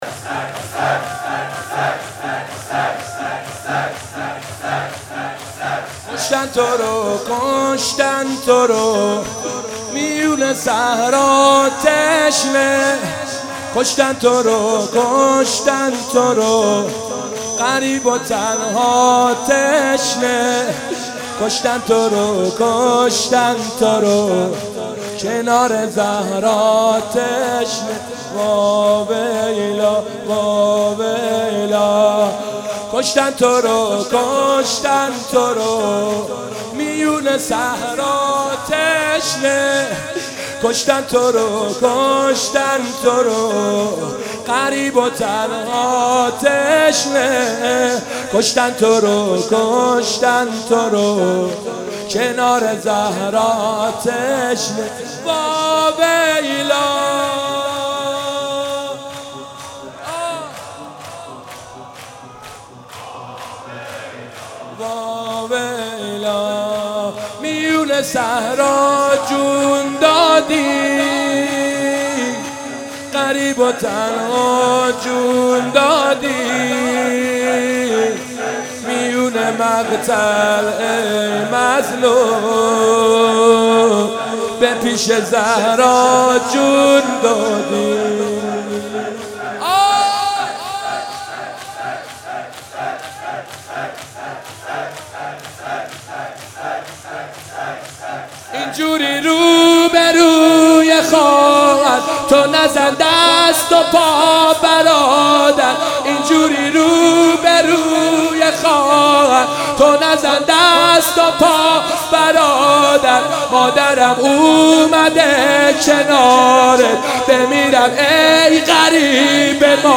دودمه/امشبی را شه دین در حرمش مهمان است